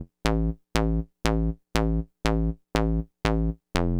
TSNRG2 Off Bass 004.wav